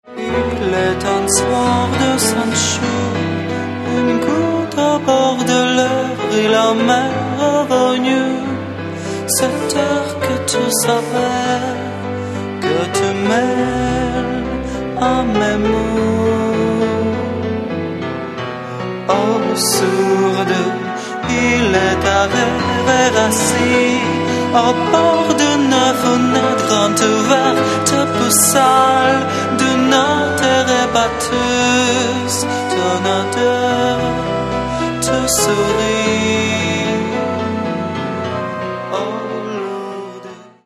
Каталог -> Поп (Легкая) -> Сборники
синт-поп и неоромантика